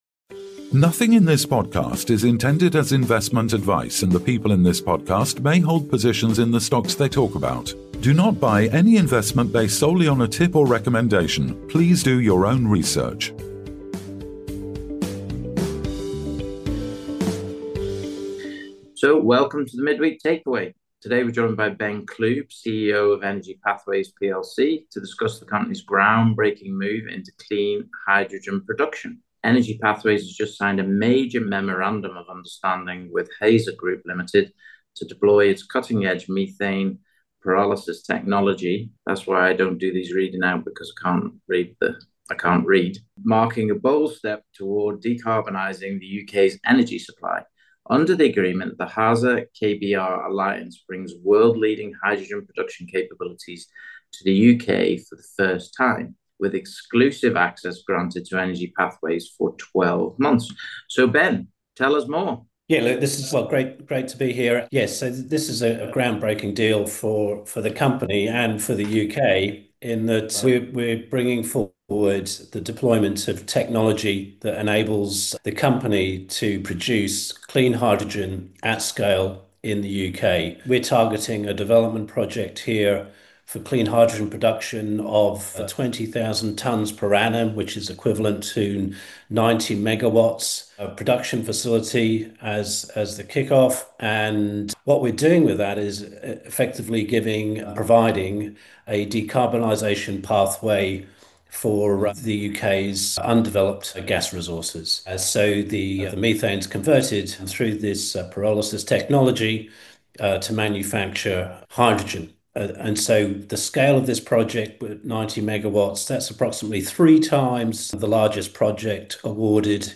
A forward-looking conversation at the intersection of energy innovation, sustainability, and national resilience.